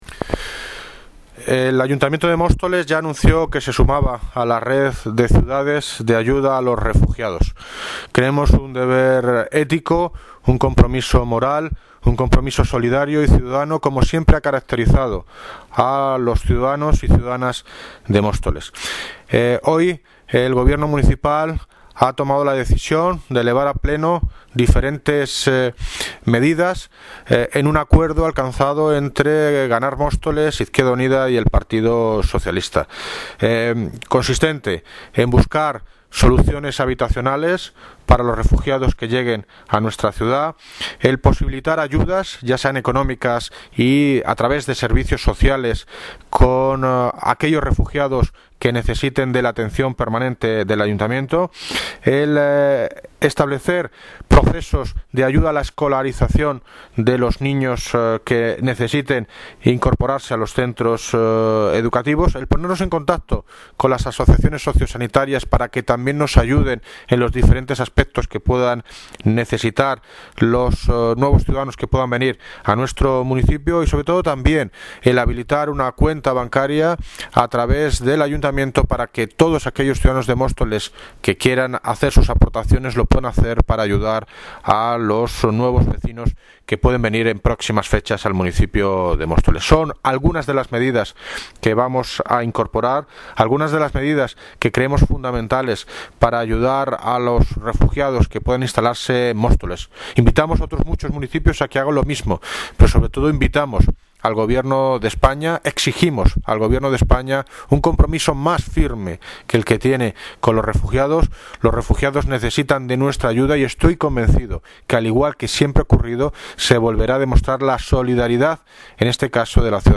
Audio - David Lucas (Alcalde de Móstoles) Sobre medidas para acoger a refugiados Móstoles